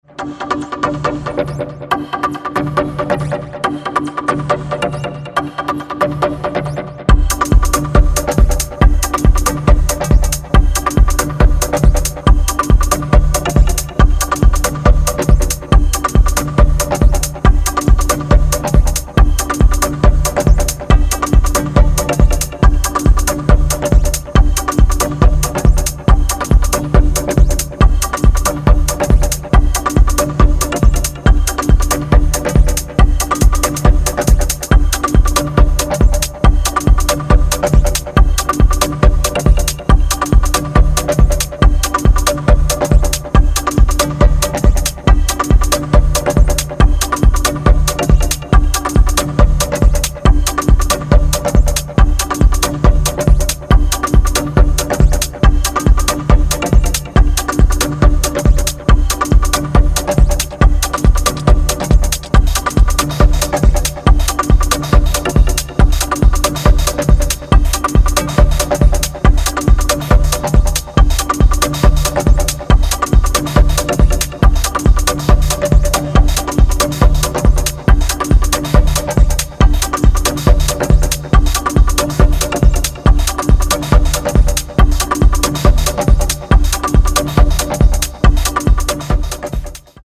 ダビーなハイピッチリフが浮遊するデトロイト・ミニマル